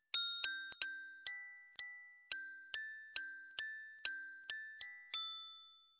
描述：Recording of a ringing of the Angelus in the Collegiate church of Santa Maria de Baiona in Baiona (Pontevedra).15th august 2015, 20:57:32.MS side micro level: angle amplitude of 90 degrees.
标签： bells ringingoftheAngelus Baiona chime SantaMariadeBaiona ValMinhor bell collegiatechurch fieldrecording
声道立体声